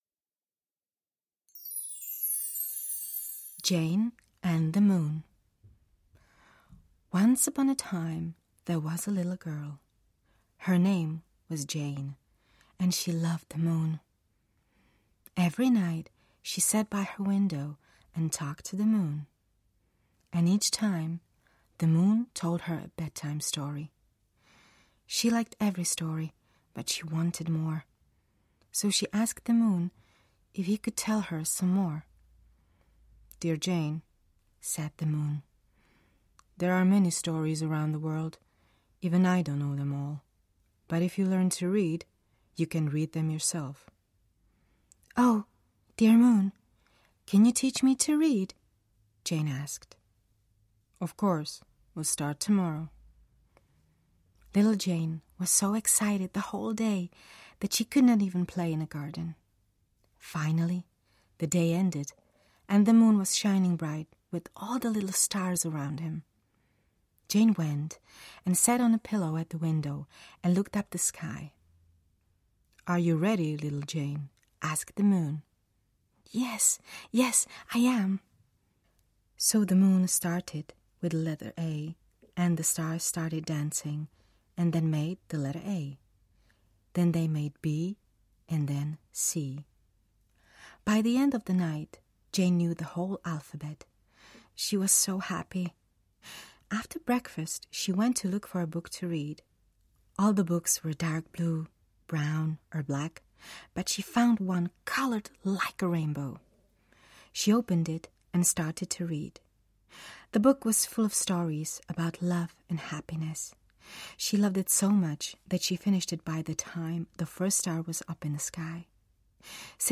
Jane and the Moon audiokniha
Ukázka z knihy